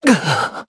Esker-Vox_Dead_jp.wav